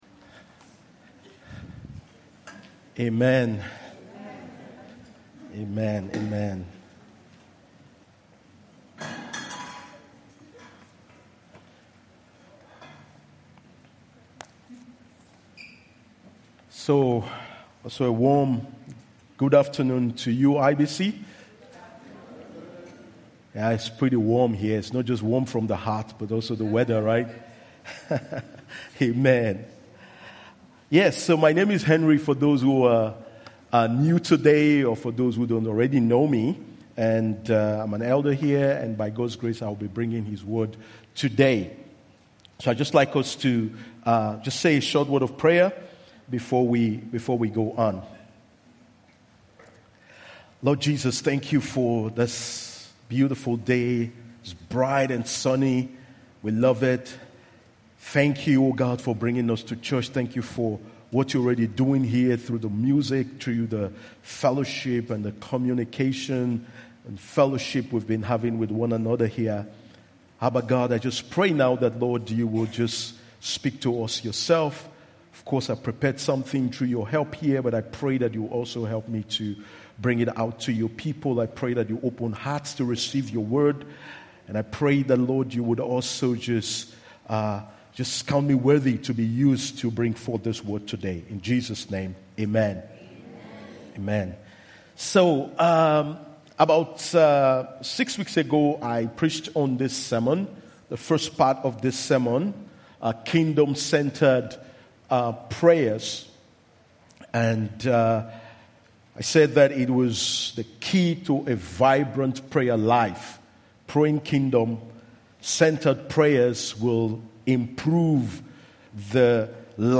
IBC Hamburg Sermon